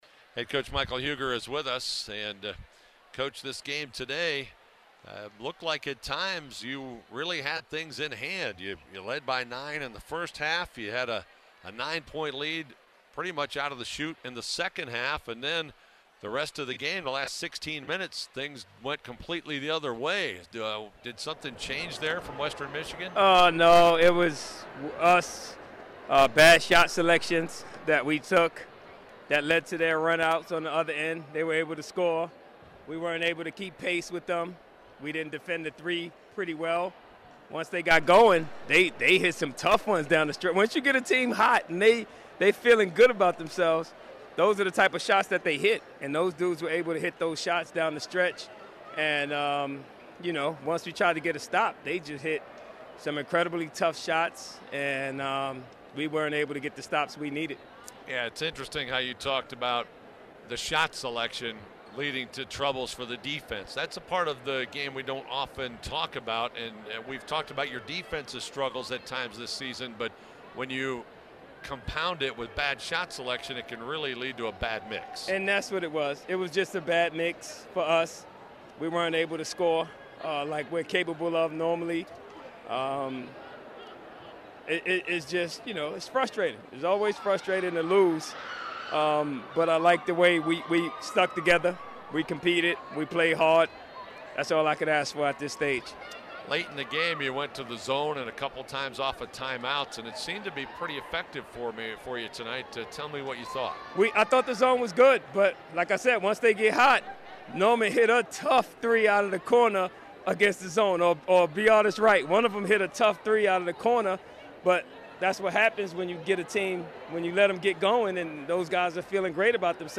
Full Postgame Interview